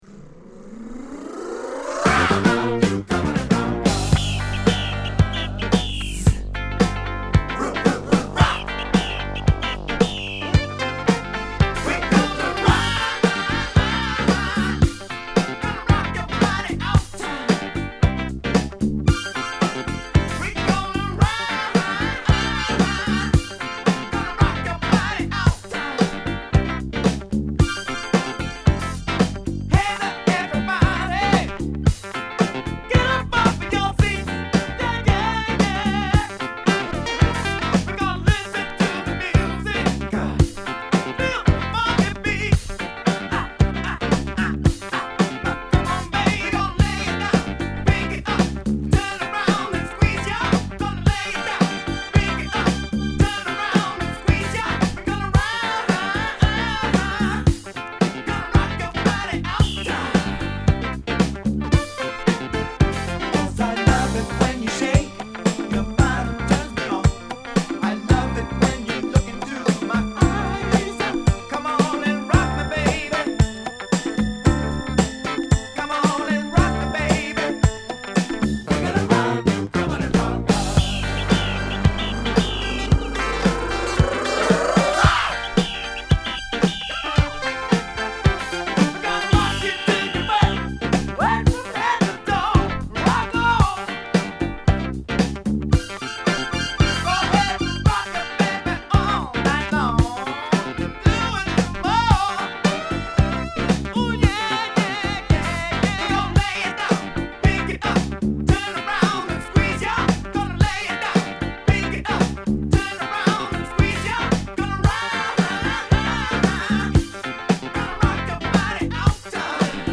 ディスコ・バンド